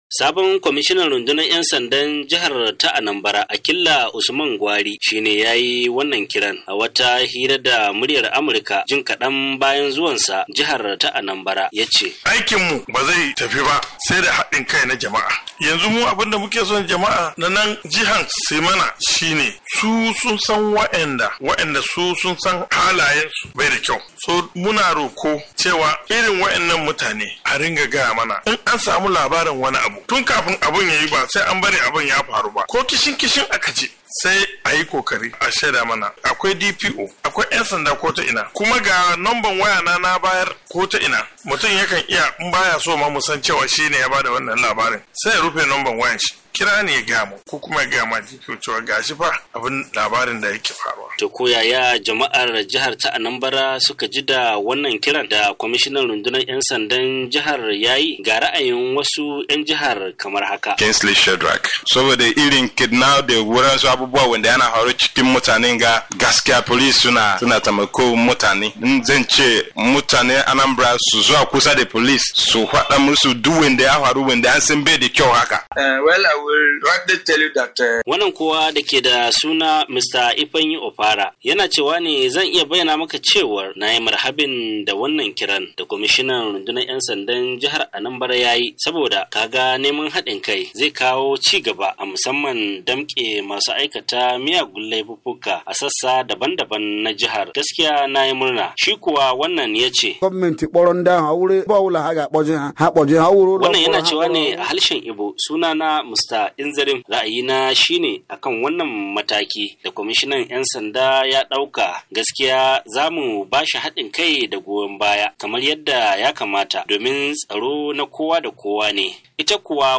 A hirasa ta farko da wakilin Sashen Hausa na Muriyar Amurka, Kwamishina Akilla Usman Gwari, yace wajibi ne jama'a su baiwa 'Yansanda hadin kai, kuddin ana son samun nasarar inganta harkokin tsaro.